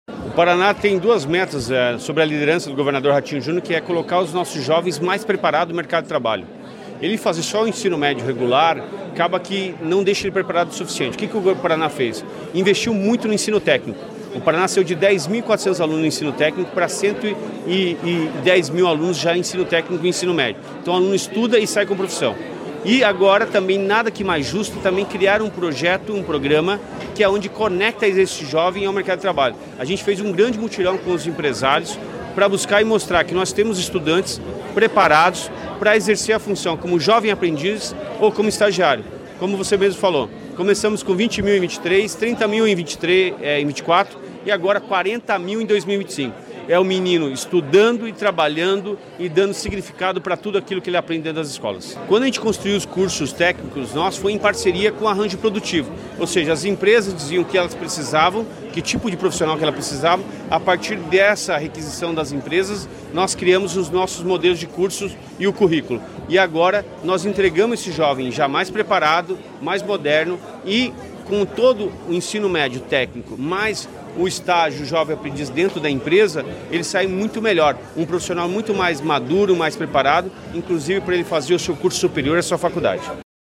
Sonora do secretário da Educação, Roni Miranda, sobre o lançamento da iniciativa Conexão Empregabilidade